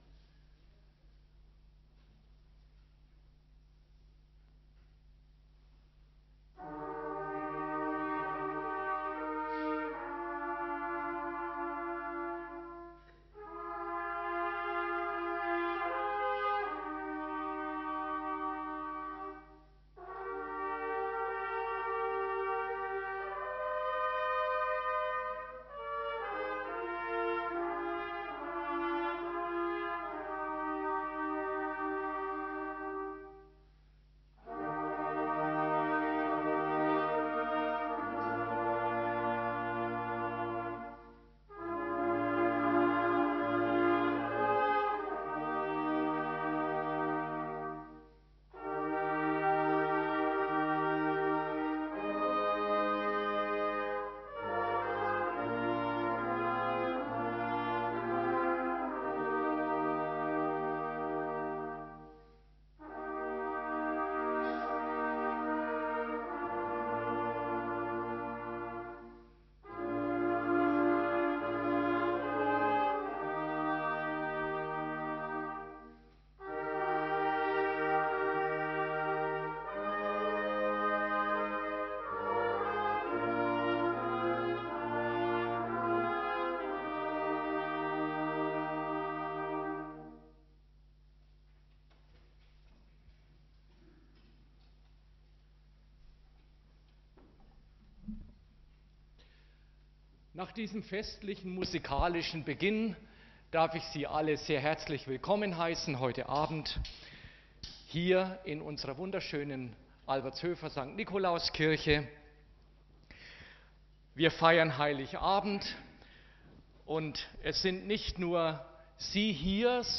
241224_18_Christvesper_k.mp3